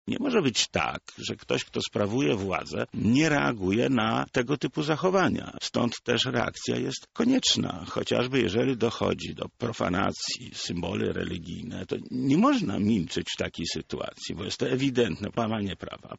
Polityk Prawa i Sprawiedliwości był gościem Porannej Rozmowy Radia Centrum.